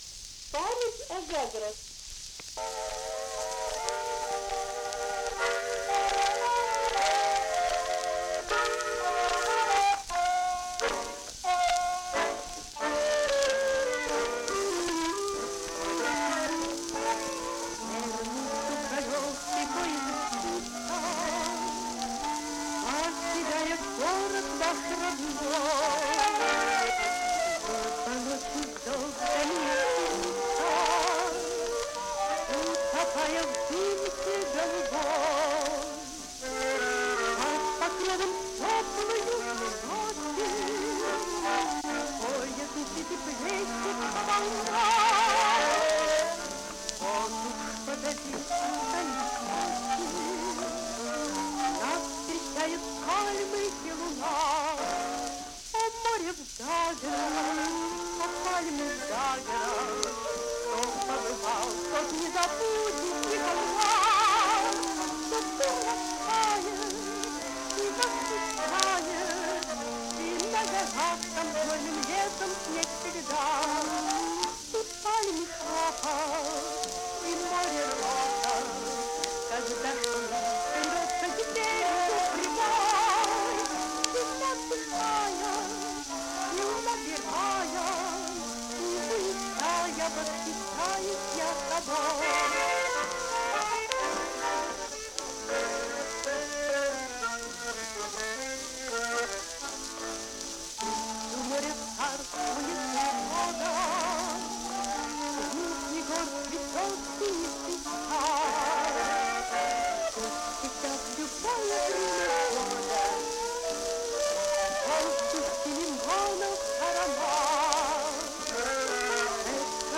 У меня среди многих исполнений есть и женское.Из стареньких.